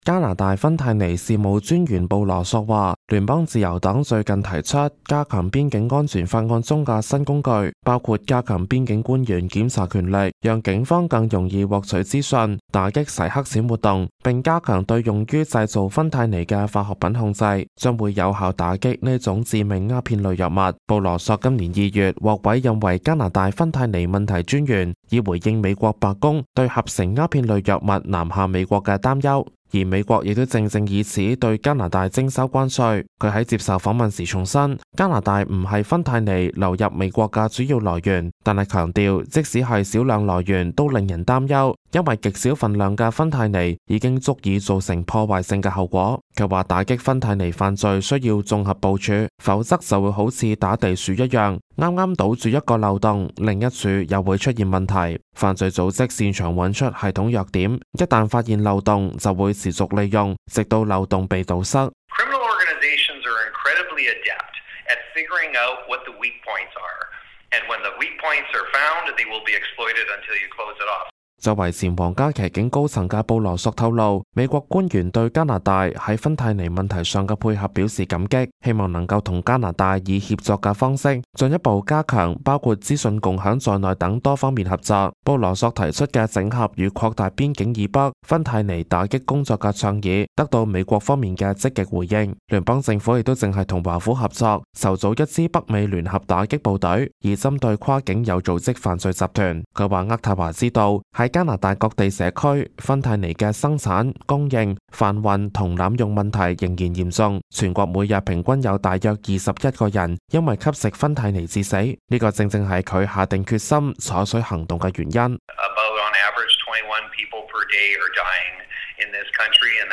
news_clip_23737.mp3